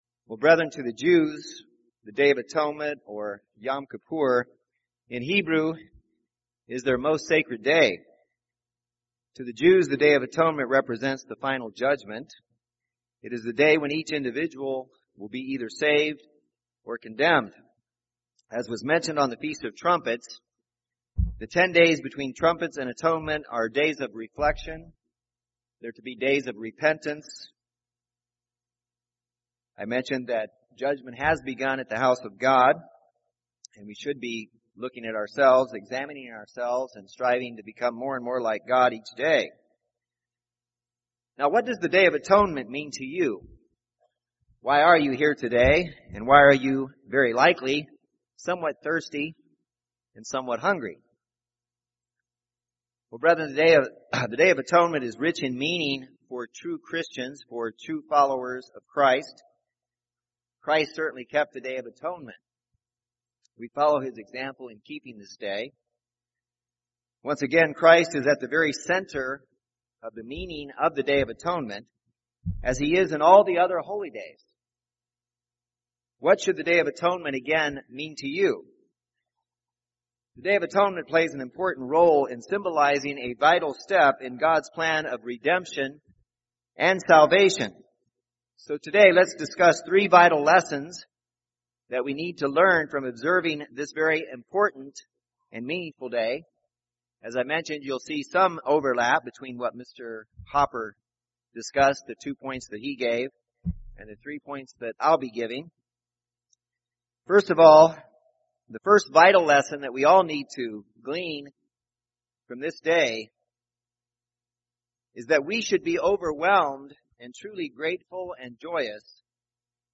The Day of Atonement plays an important role in symbolizing a vital step in God’s plan of redemption and salvation. In this sermon we will discuss three vital lessons we need to learn from observing this very important and meaningful day.